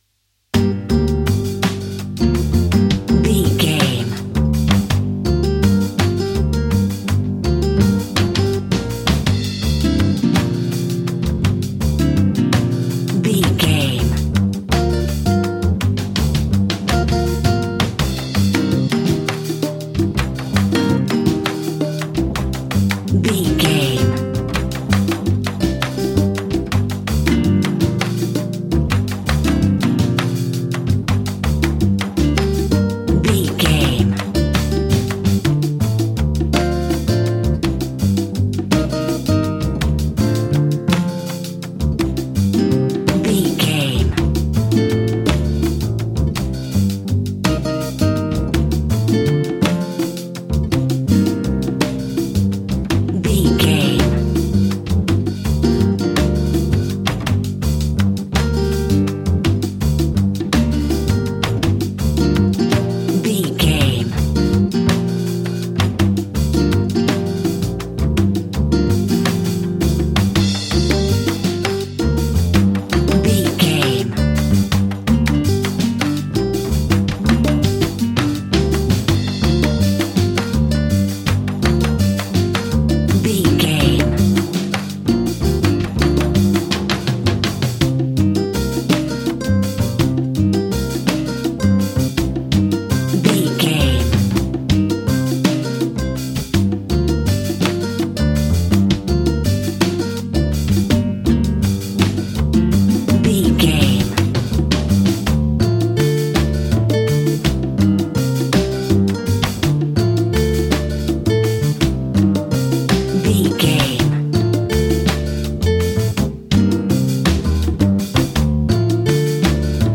An exotic and colorful piece of Espanic and Latin music.
Aeolian/Minor
funky
energetic
romantic
percussion
electric guitar
acoustic guitar